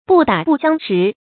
注音：ㄅㄨˋ ㄉㄚˇ ㄅㄨˋ ㄒㄧㄤ ㄕㄧˊ
不打不相識的讀法